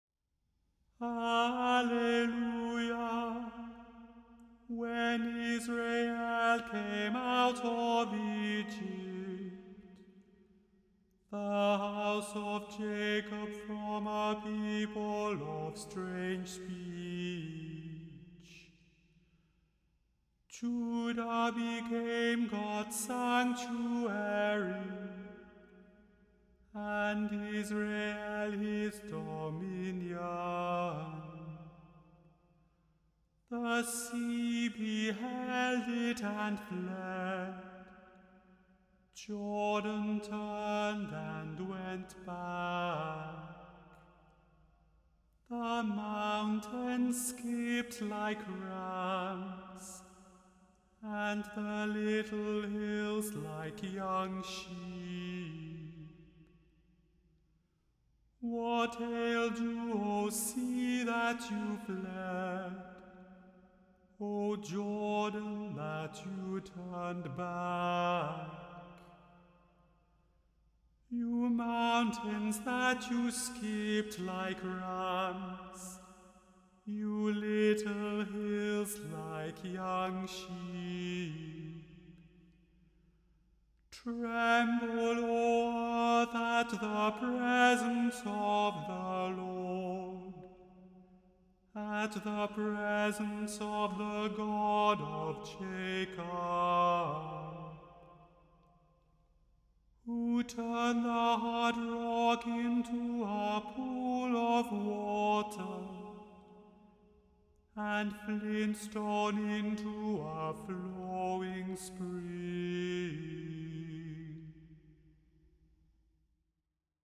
The Chant Project – Chant for Today (April 16) – Psalm 114 – Immanuel Lutheran Church, New York City